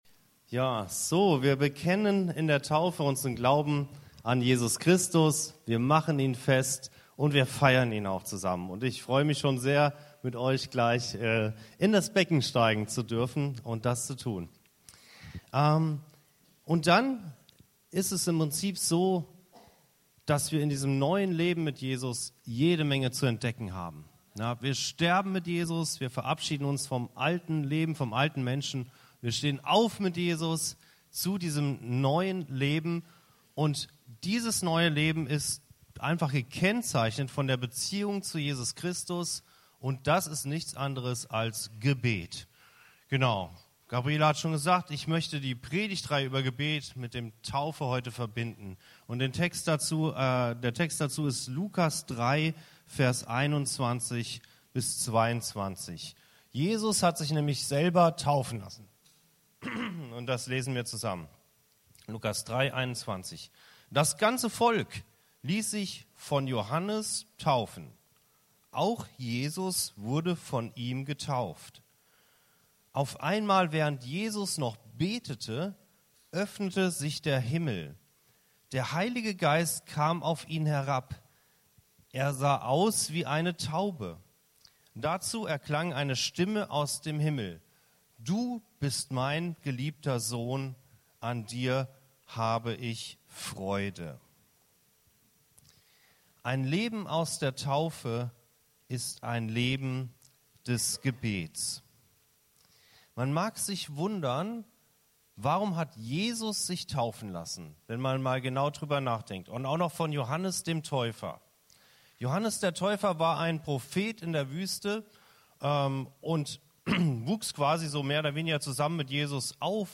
Beschreibung vor 1 Jahr Gebet öffnet den Himmel, erfüllt uns mit dem Heiligen Geist und zeigt uns unsere geistliche Identität. In dieser Predigt aus der Reihe Gebet entdecken wir, warum ein Leben aus der Taufe ein Leben des Gebets ist. Jesus selbst zeigt uns den Weg: Durch das Gebet erfahren wir Gottes Gegenwart, seine Kraft und unsere Bestimmung als seine geliebten Kinder.